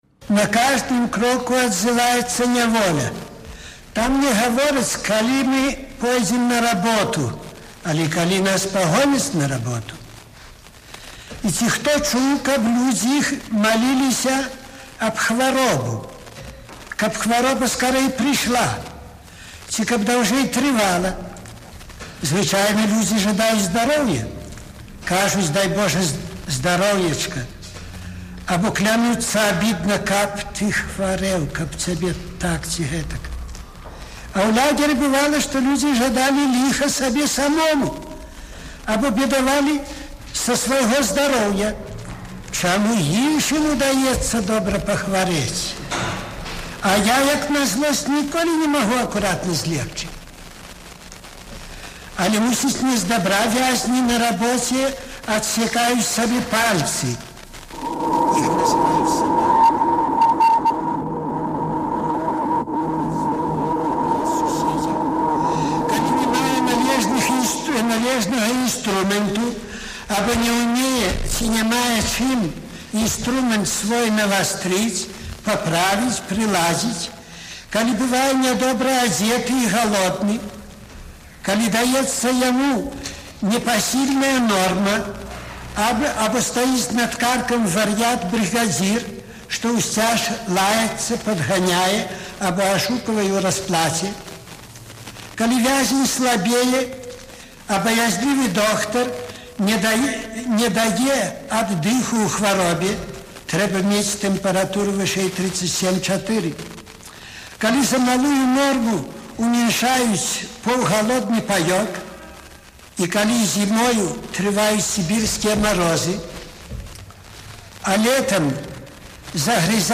Аўтэнтычны эфір 1961 году.